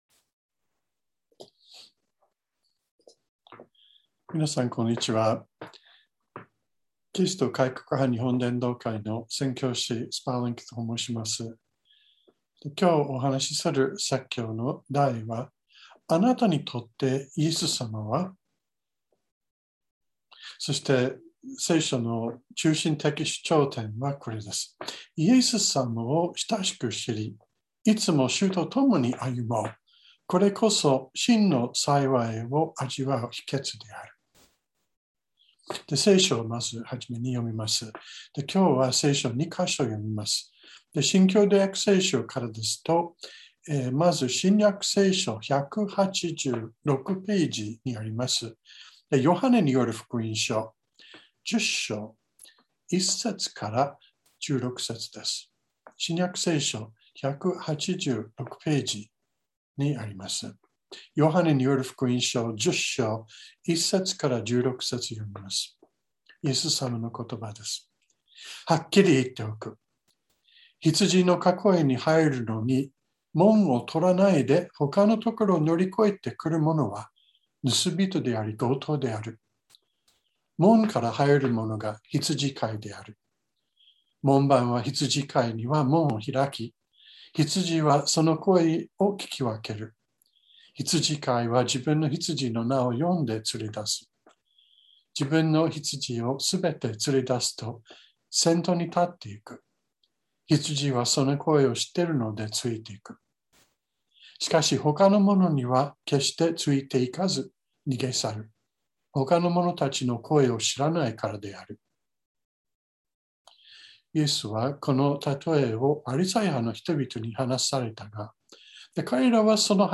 川越教会。説教アーカイブ。